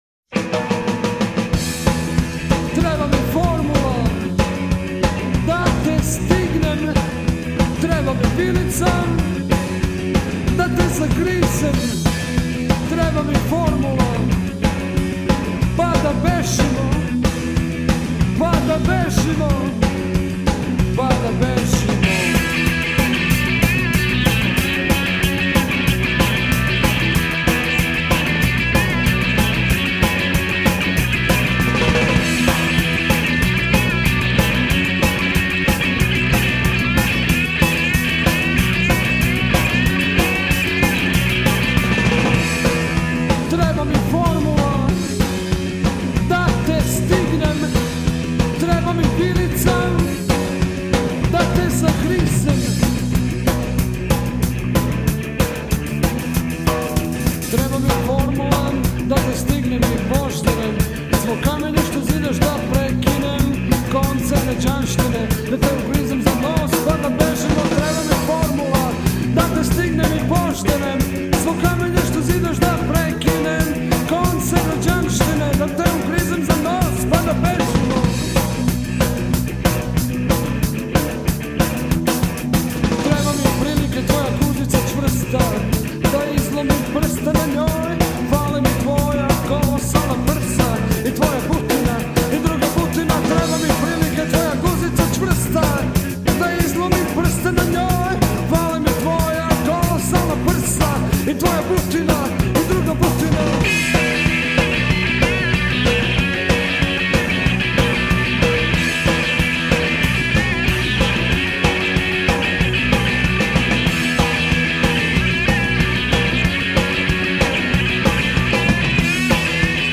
od ritma i bluza, do pank-roka.
Vokal
gitara
bas gitara
bubanj